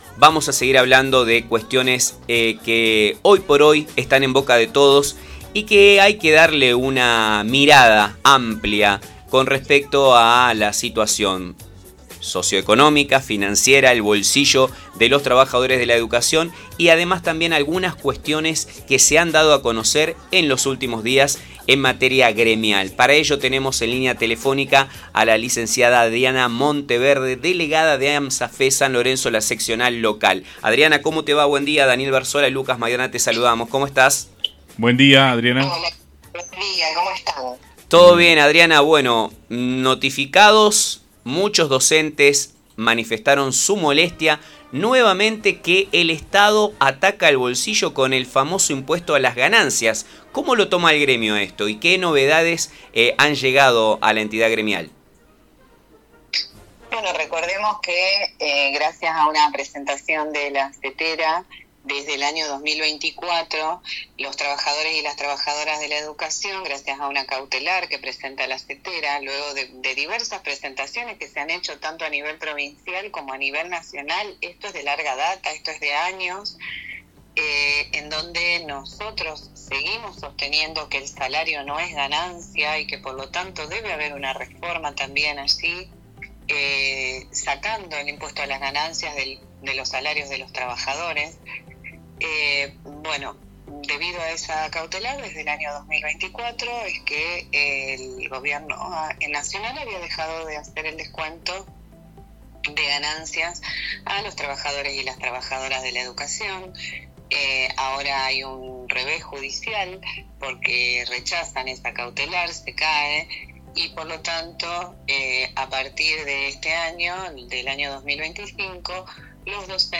Durante una entrevista concedida al programa radial El Muelle de Nueva Estrella Medios